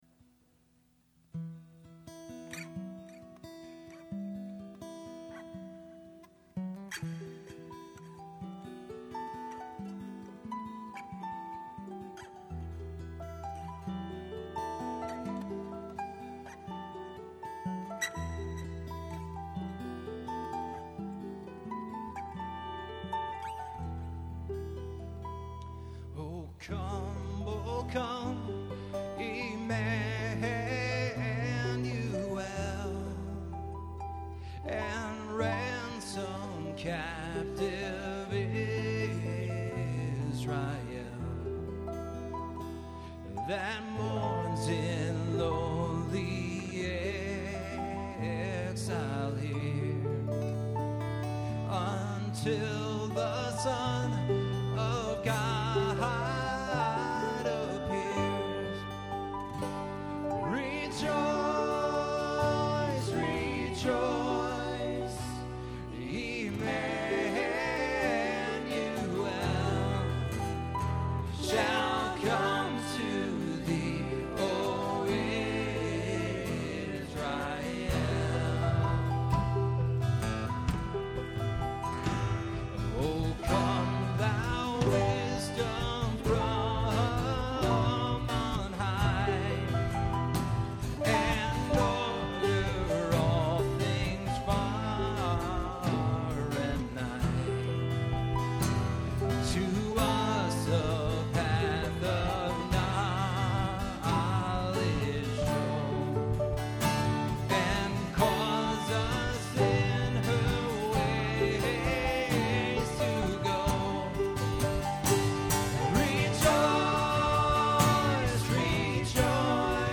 Performed live during Advent at Terra Nova-Troy by The Terra Band on 11.30.08.